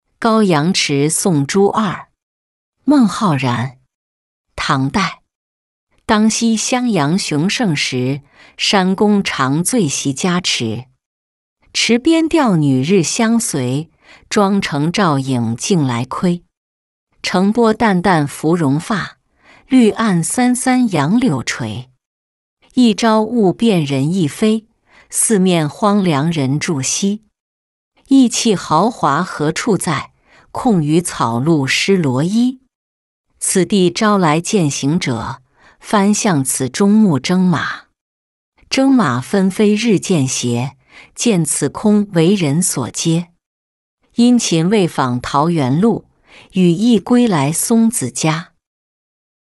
高阳池送朱二-音频朗读